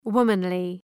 Προφορά
{‘wʋmənlı}